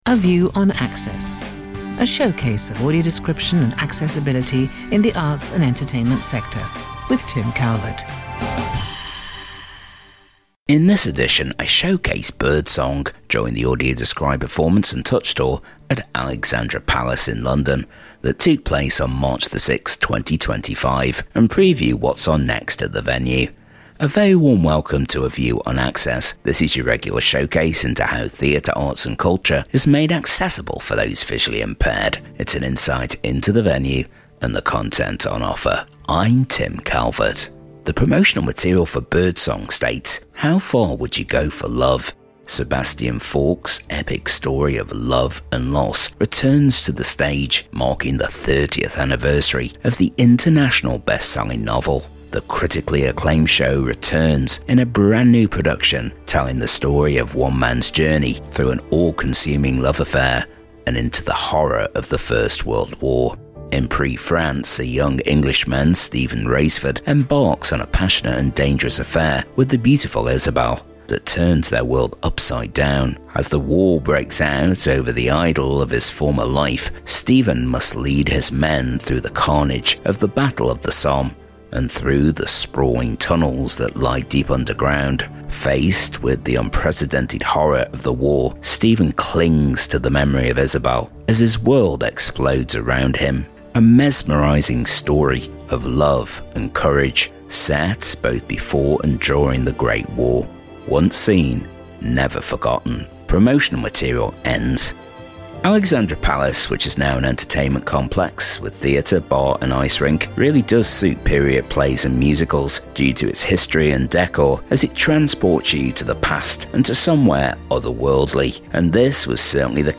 In this edition I showcase birdsong during the audio described performance and touch tour at Alexandra Palace that took place on March the 6th and preview the audio described performance of North by Northwest on Tuesday the 17th of June at 7.30pm